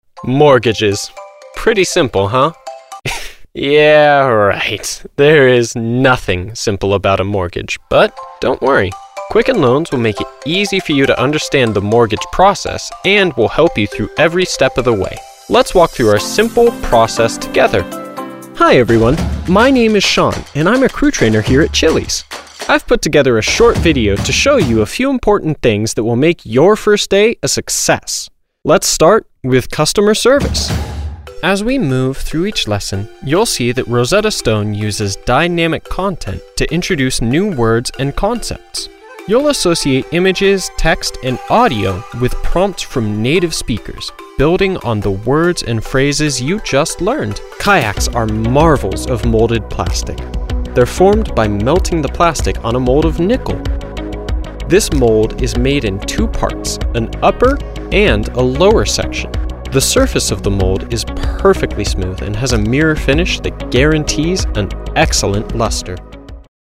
A voice that's Trustworthy, energetic, and down to earth
Narration Demo
Teen
Young Adult